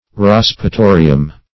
Search Result for " raspatorium" : The Collaborative International Dictionary of English v.0.48: Raspatorium \Ras`pa*to"ri*um\ (r[a^]s`p[.a]*t[=o]"r[i^]*[u^]m), n. [LL.]